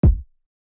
TS Kick 4.wav